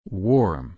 /wɔːm/